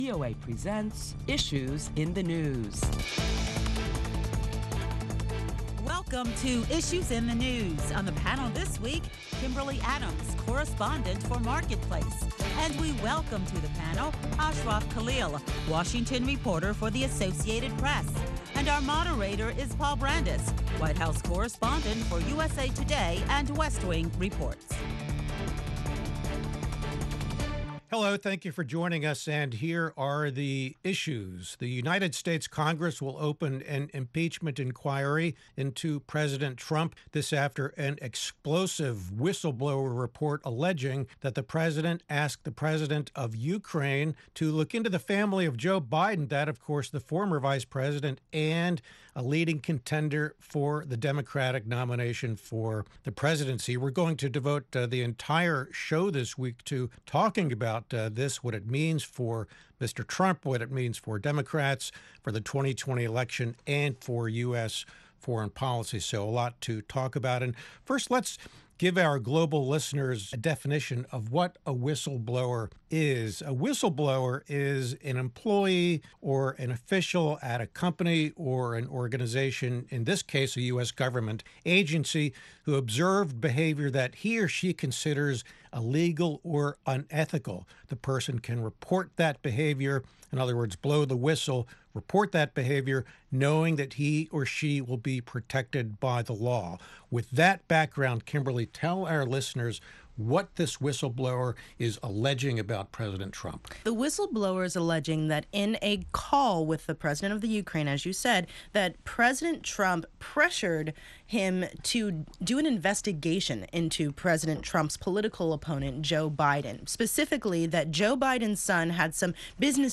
Listen to a panel of prominent Washington journalists as they deliberate the impact of the impeachment process on U.S. politics and Democrat and Republican reaction to the whistle blower report.